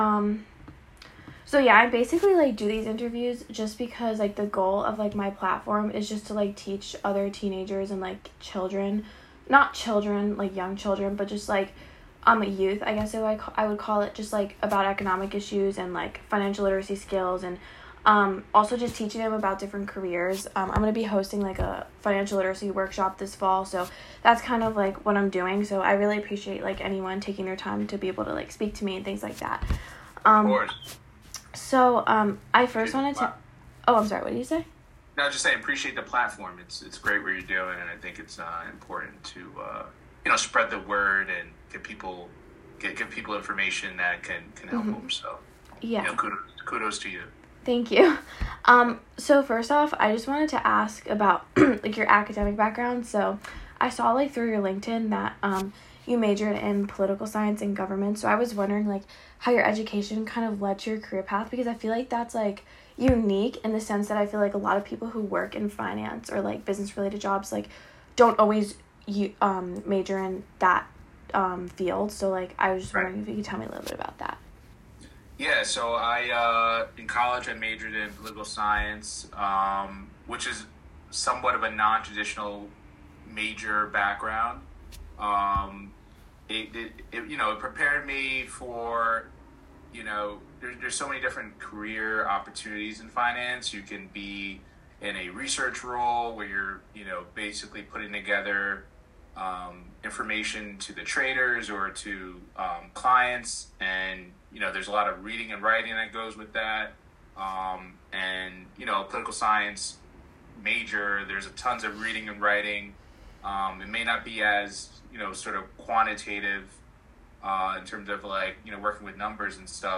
Career Talk